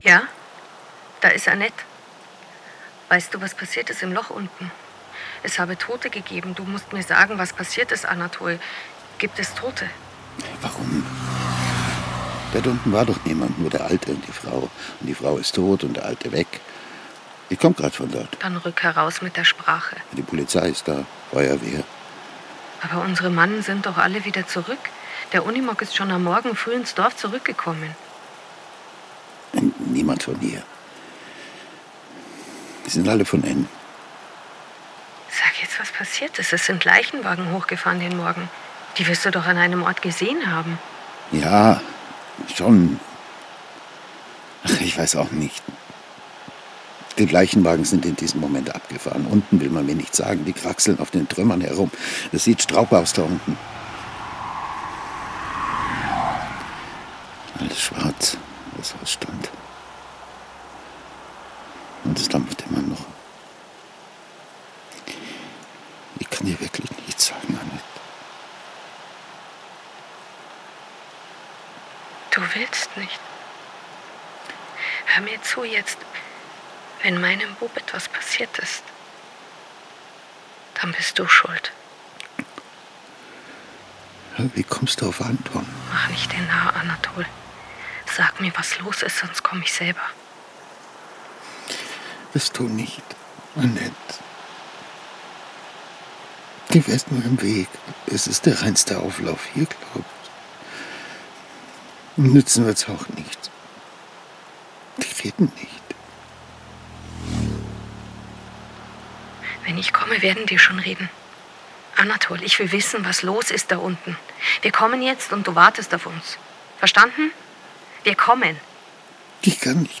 Radio | Hörspiel
Hörspiel nach dem gleichnamigen Roman
Das Guggisberglied, eine Schweizer Volksweise, in der der titelgebende Simeliberg refrainartig auftaucht, gibt den Ton der Erzählung und auch des Hörspiels an: Düster, melancholisch.
Neuverdichtung als poetisches Hörstück
Es klingt mal melancholisch, mal schaurig düster und mal groovy.“